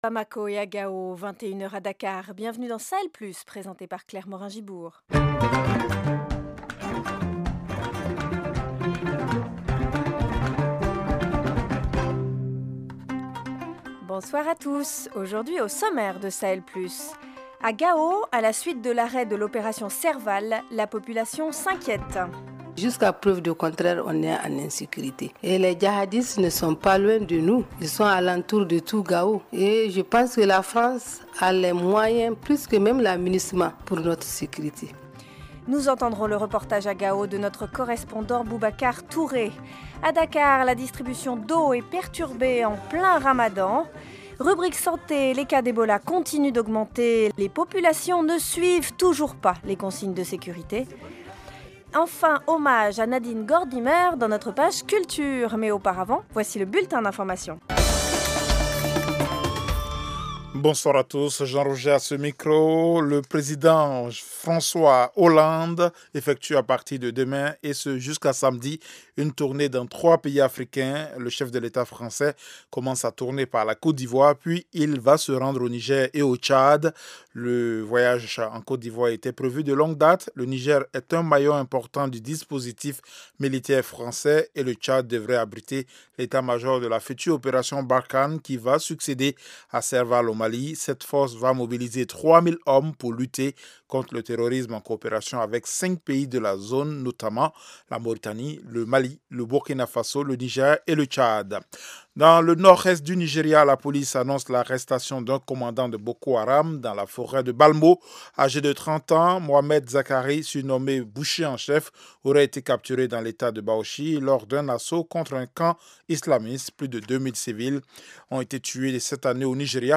Au programme : la population s’inquiète à Gao au Mali à la suite de l’arrêt de l’opération Serval. Reportage